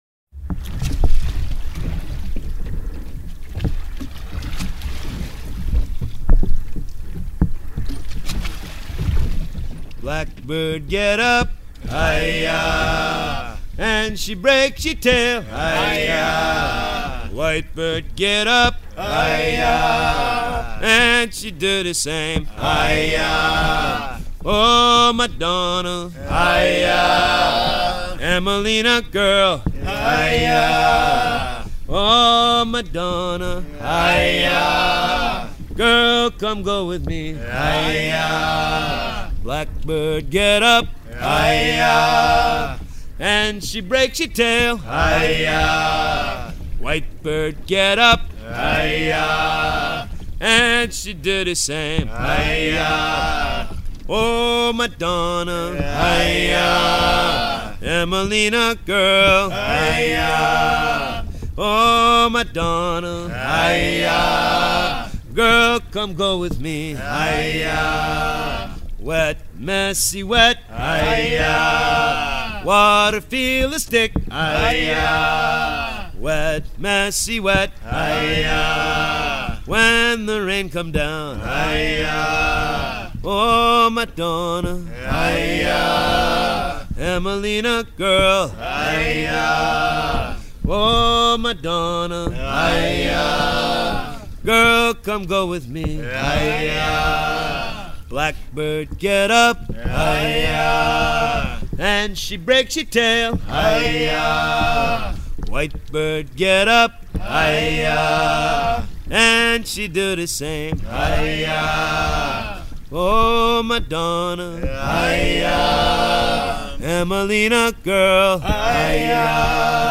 à ramer
Pièce musicale éditée